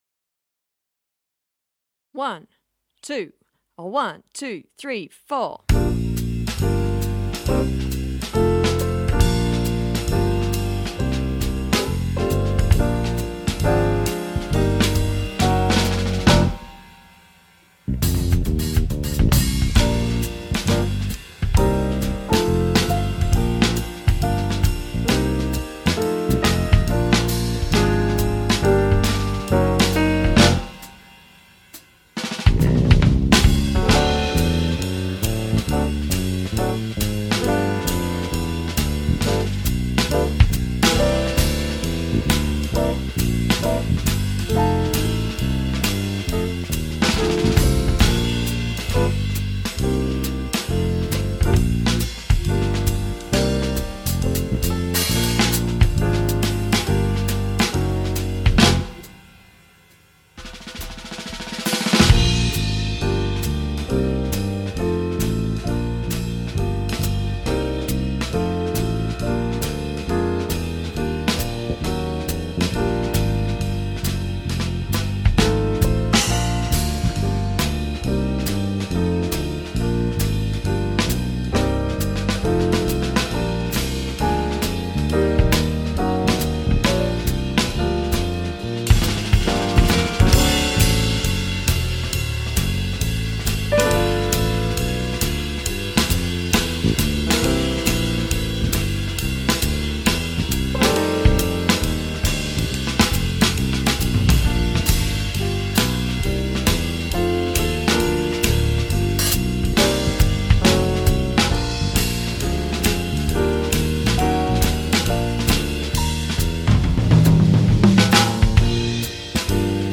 RC Backing Alto Sax